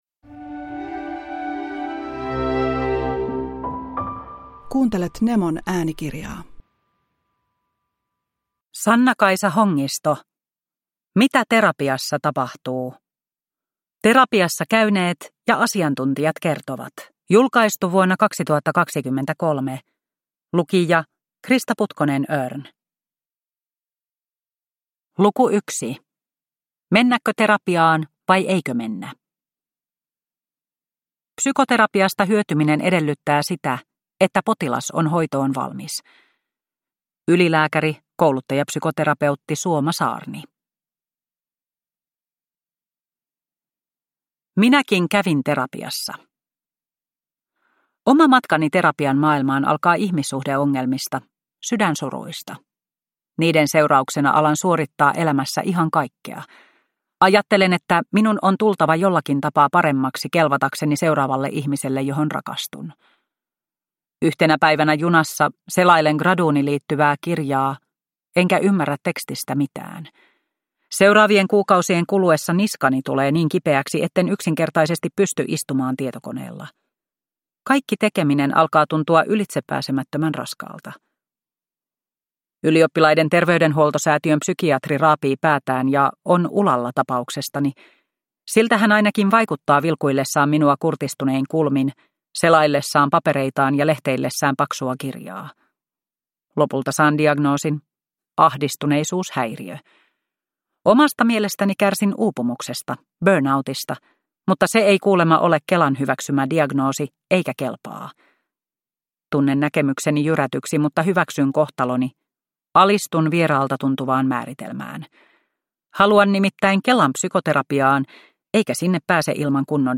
Mitä terapiassa tapahtuu? – Ljudbok – Laddas ner